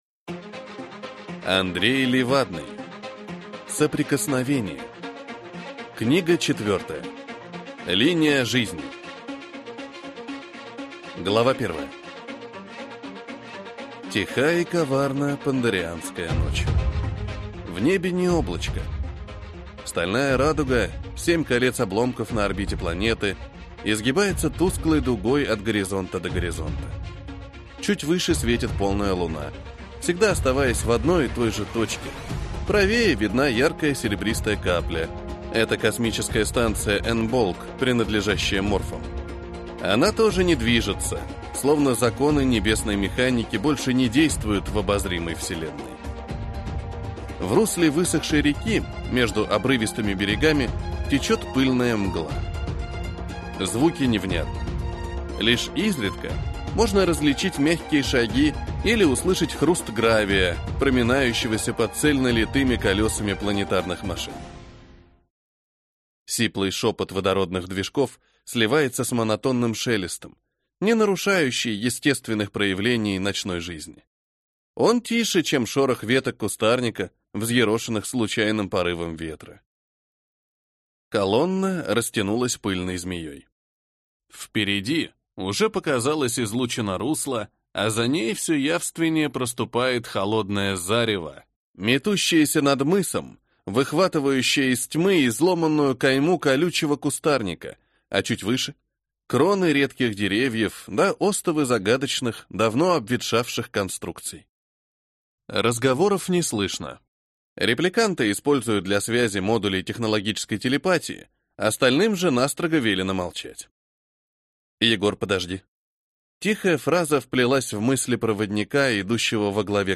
Аудиокнига Линия жизни | Библиотека аудиокниг